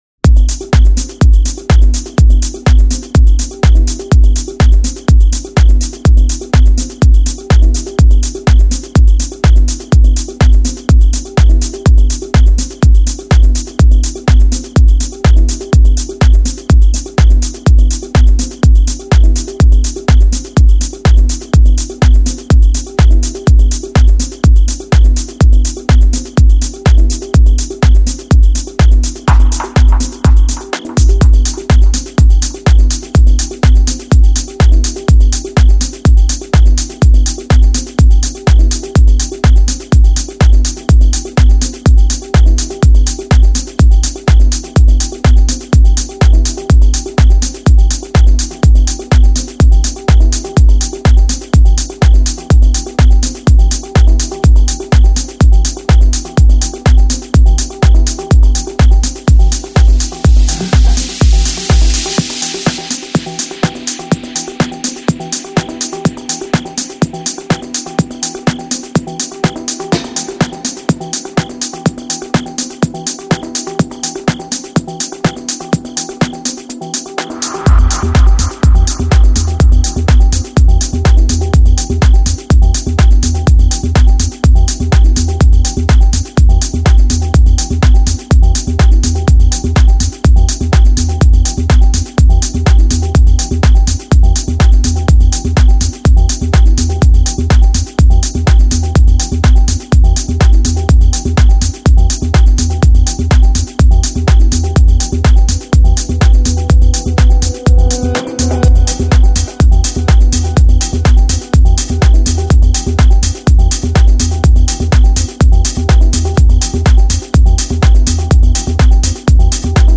Deep House, Techno, Tech House, Progressive House